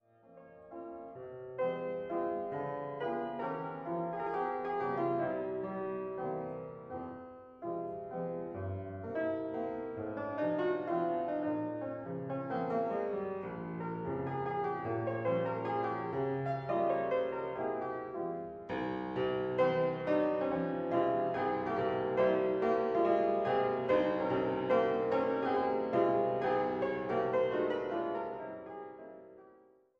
Sprecher
Hammerflügel